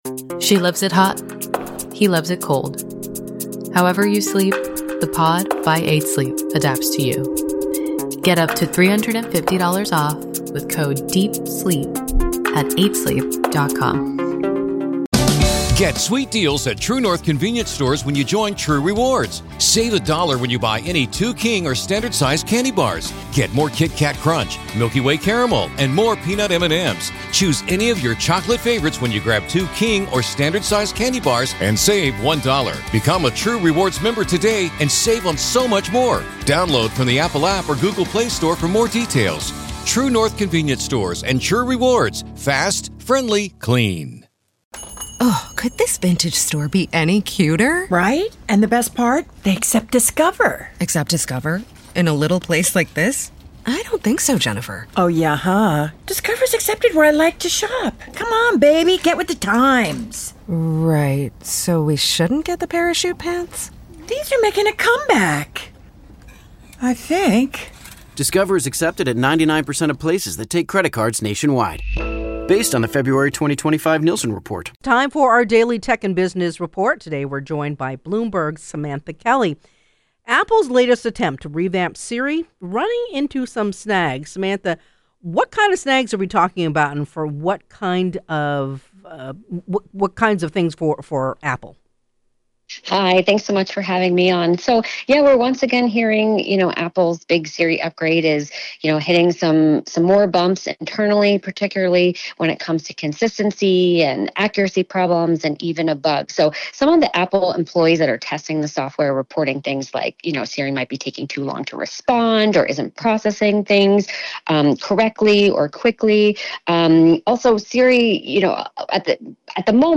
This is the daily Tech and Business Report.